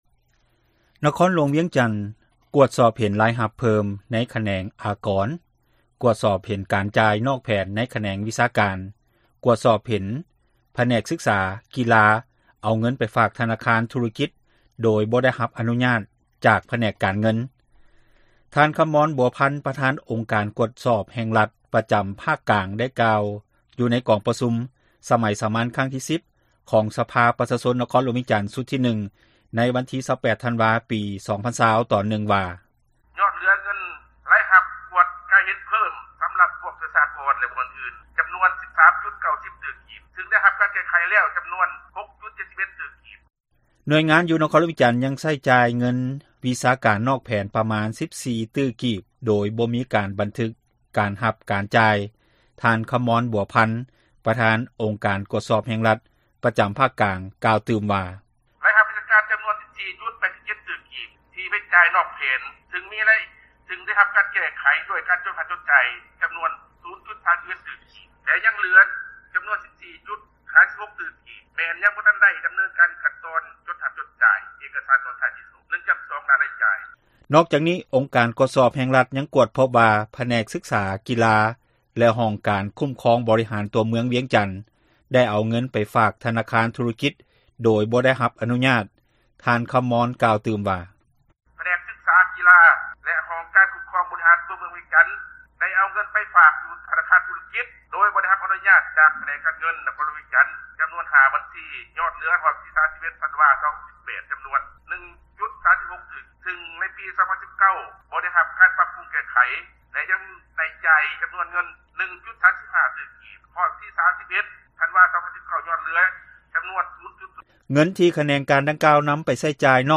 ນະຄອນຫຼວງ ກວດພົບ 30 ຕື້ກີບ ຈ່າຍນອກແຜນ ທ່ານ ຄຳມອນ ບົວພັນ, ປະທານກວດກາ ພັກ-ຣັຖ ປະຈຳພາກກາງ ຣາຍງານຕໍ່ກອງປະຊຸມ ສະພາປະຊາຊົນ ນະຄອນຫຼວງວຽງຈັນ ສມັຍສາມັນເທື່ອທີ 10 ຂອງສະພາປະຊາຊົນ ນະຄອນຫຼວງວຽງຈັນ ຊຸດທີ 1 ເມື່ອວັນທີ 28 ທັນວາ 2020